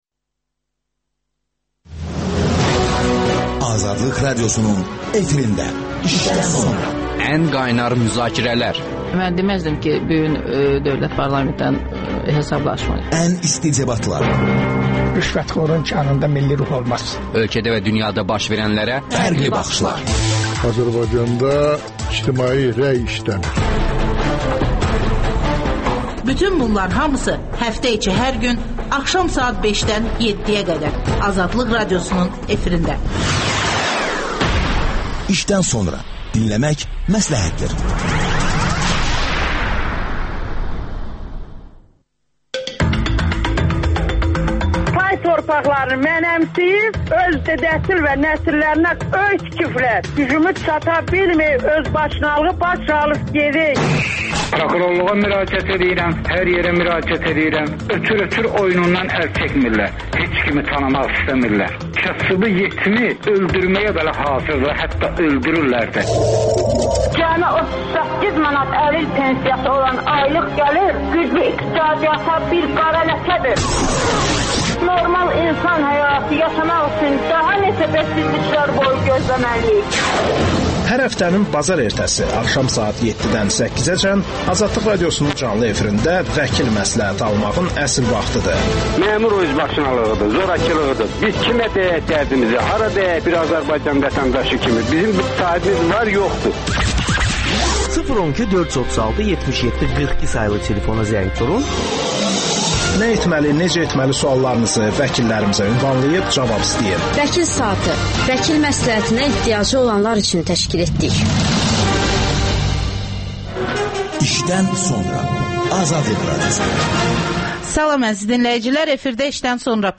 söhbət